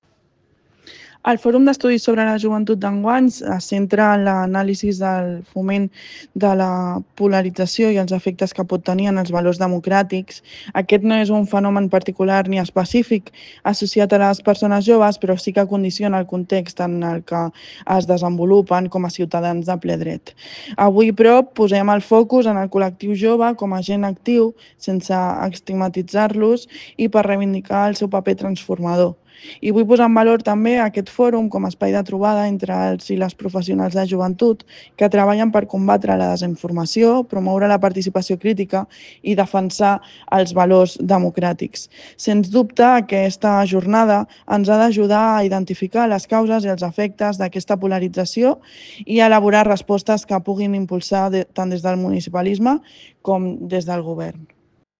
Nota de veu de la Directora General de Joventut